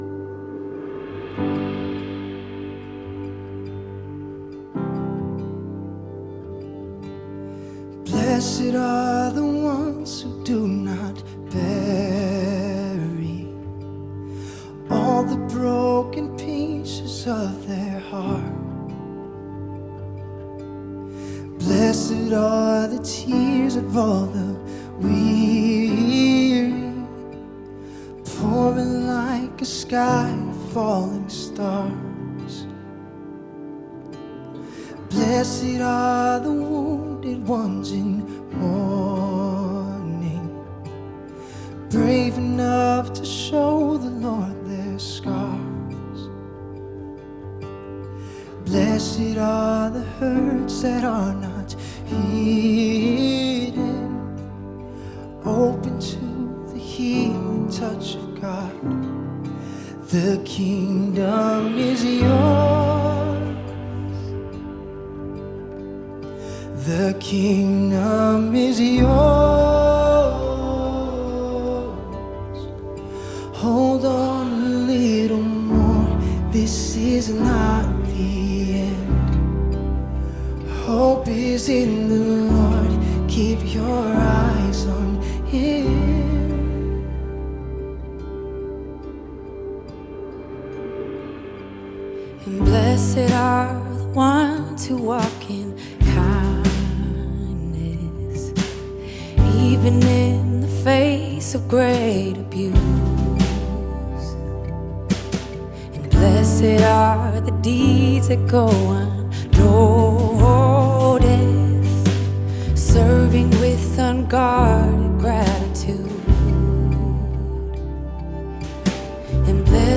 Sermons Online